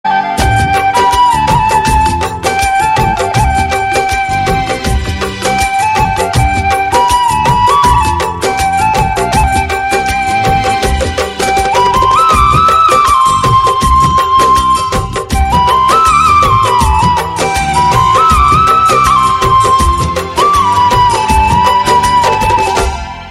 • Bollywood Ringtones